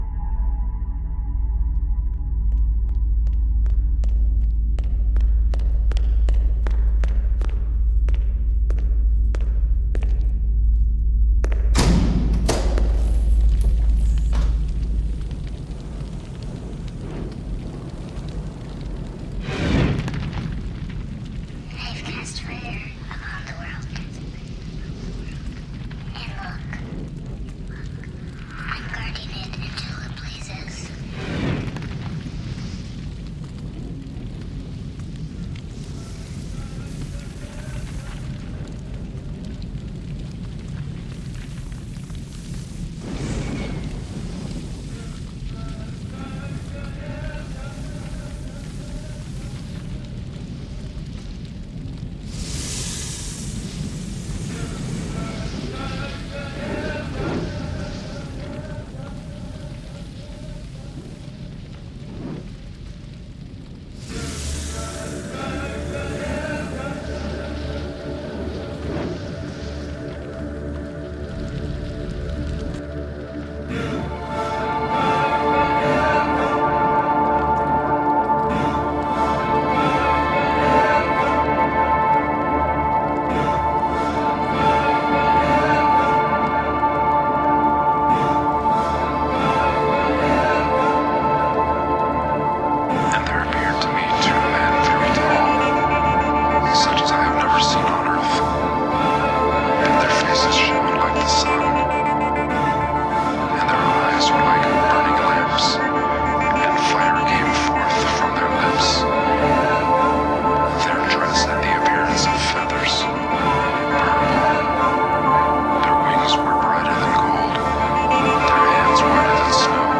Solid, sensual, organic, languorous electronic music.
ends things on a hypnotic, spirtual note.
Tagged as: World, Electronica, World Electronic